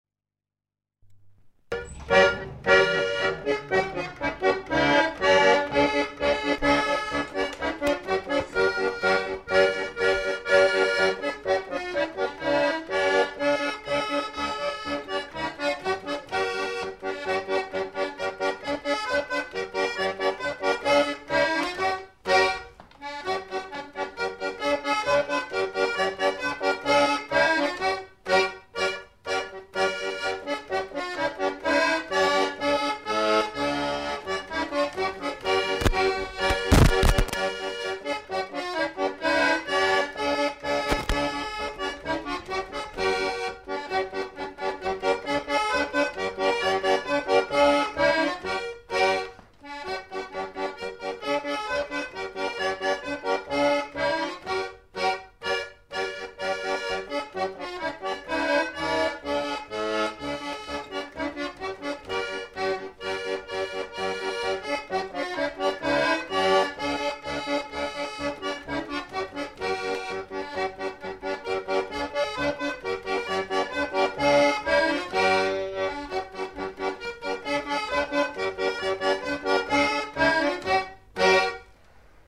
Lieu : Pyrénées-Atlantiques
Genre : morceau instrumental
Instrument de musique : accordéon diatonique
Danse : quadrille (1e f.)